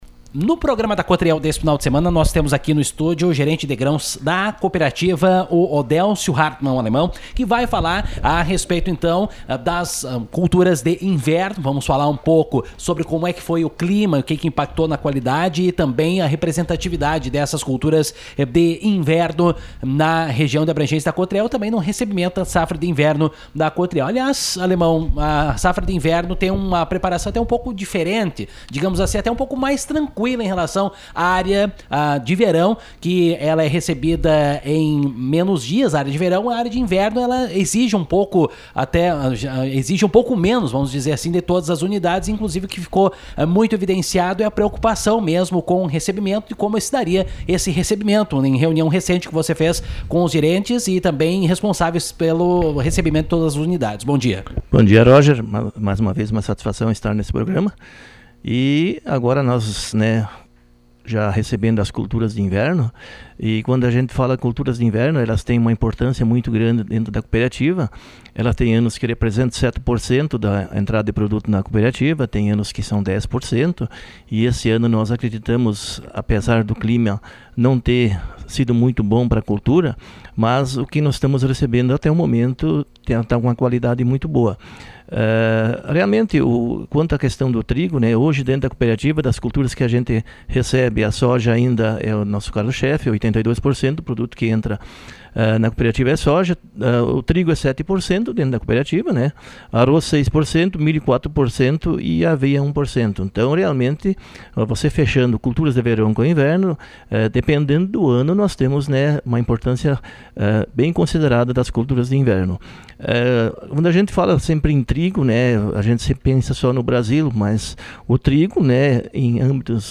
Em entrevista à nossa reportagem